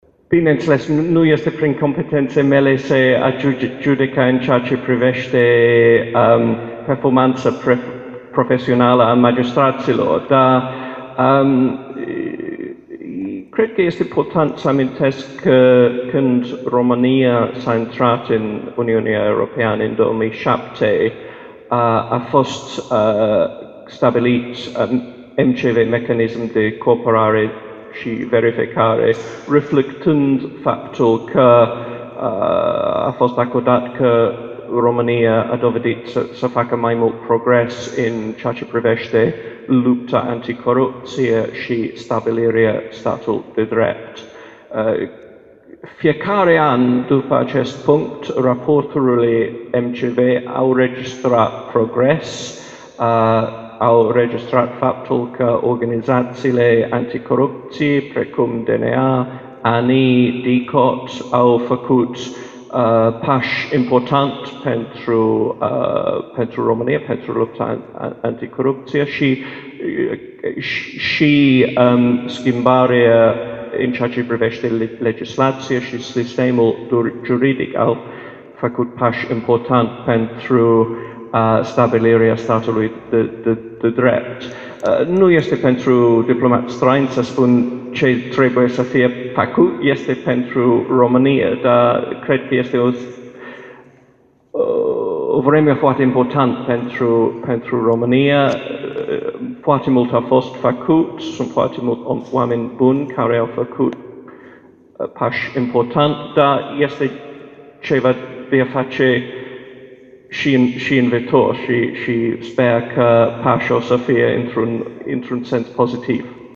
Conferința ambasadorului Marii Britanii, Paul Brummell( foto) pe teme de istorie, diplomație, dar și sociale, s-a desfășurat aștăzi în Sala a Voievozilor a Palatului Culturii.